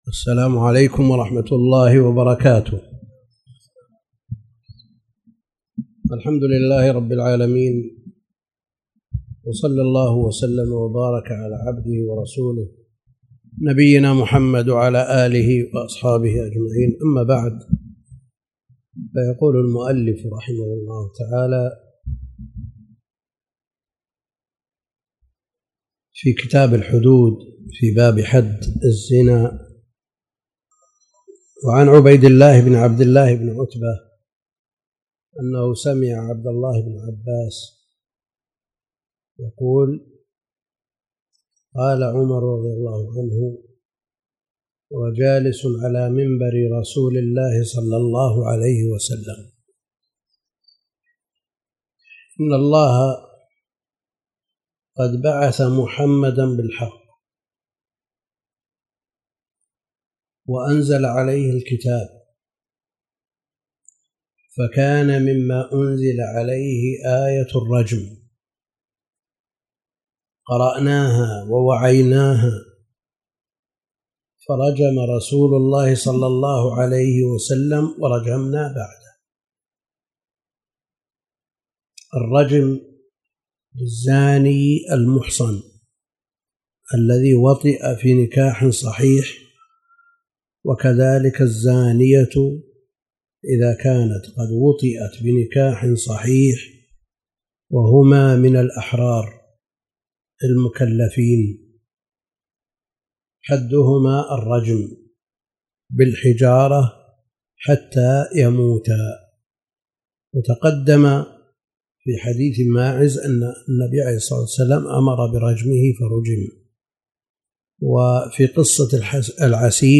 تاريخ النشر ٢٦ ربيع الأول ١٤٣٨ المكان: المسجد الحرام الشيخ: فضيلة الشيخ د. عبد الكريم بن عبد الله الخضير فضيلة الشيخ د. عبد الكريم بن عبد الله الخضير كتاب المحررفي الحديث